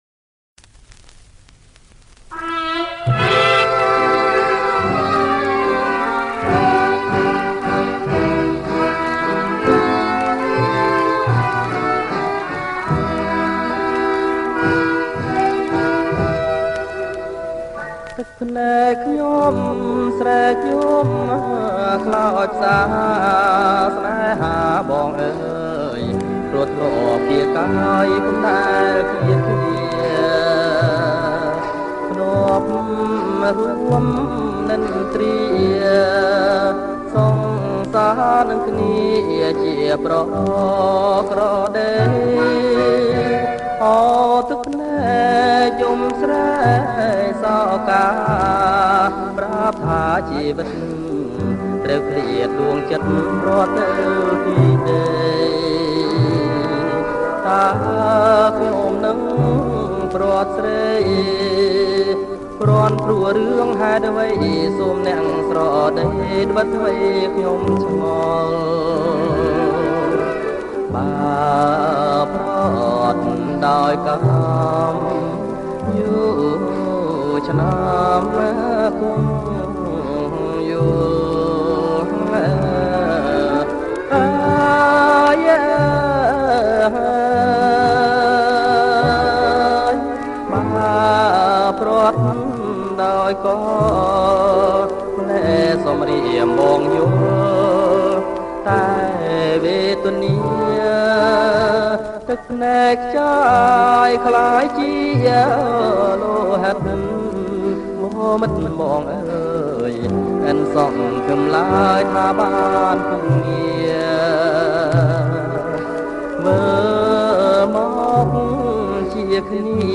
• ប្រគំជាចង្វាក់ Slow